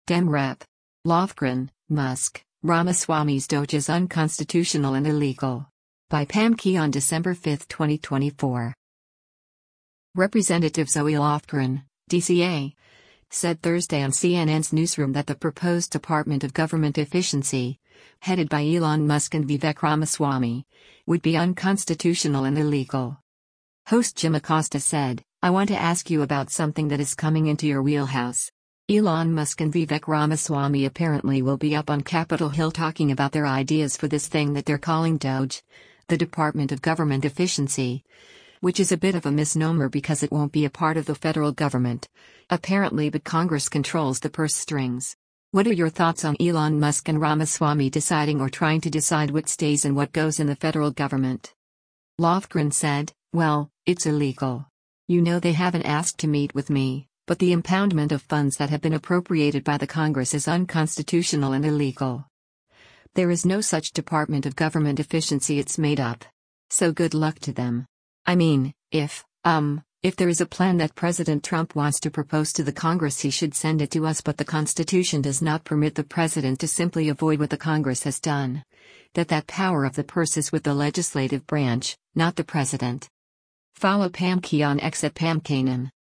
Representative Zoe Lofgren (D-CA) said Thursday on CNN’s “Newsroom” that the proposed Department of Government Efficiency, headed by Elon Musk and Vivek Ramaswamy, would be “unconstitutional and illegal.”